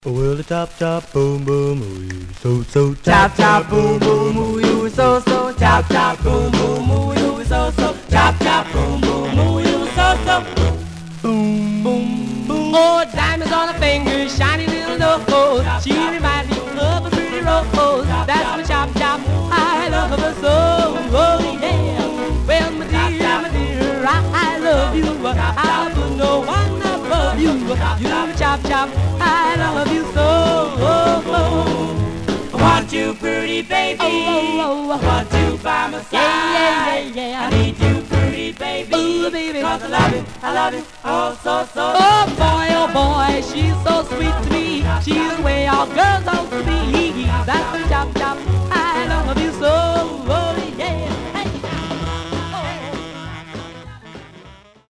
Surface noise/wear
Mono
Male Black Group Condition